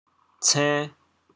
潮阳拼音 cên2
国际音标 [ts]